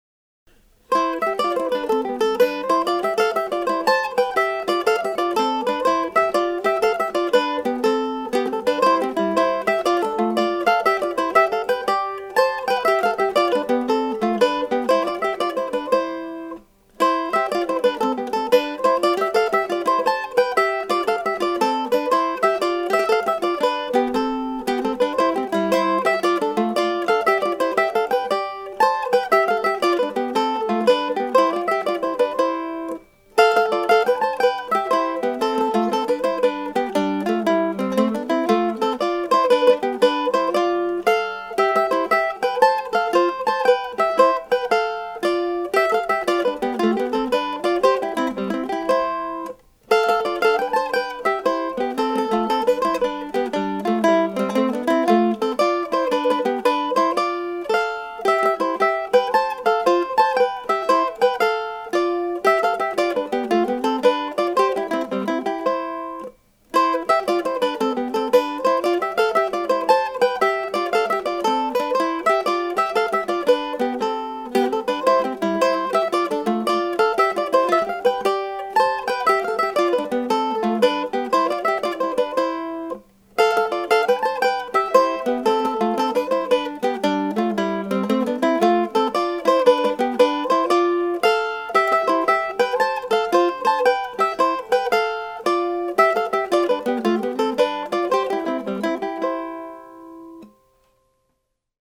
A simple, sunny start to the new year.